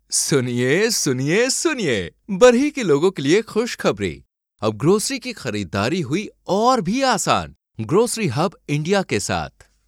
Male
Narration
Store Promotion Hindi Voice.
All our voice actors have professional broadcast quality recording studios.
0707hindi_voice_over__grocery_store_promotion.mp3